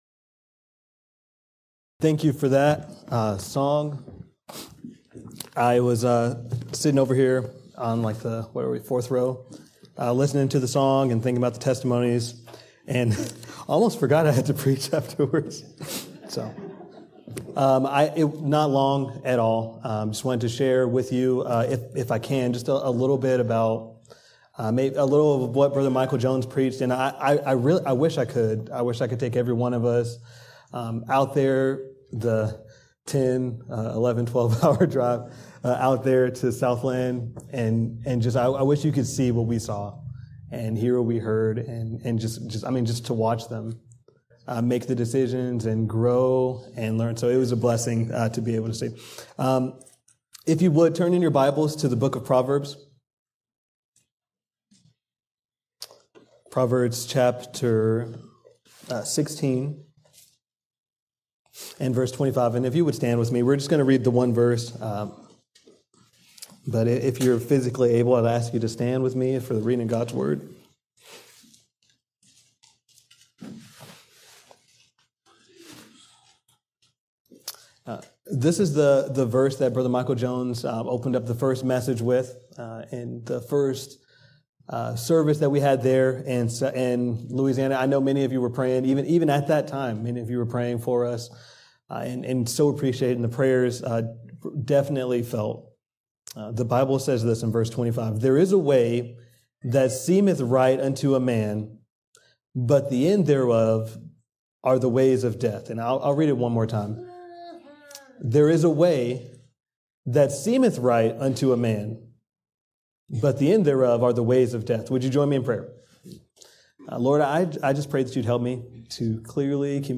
A message from the series "The Armor of God."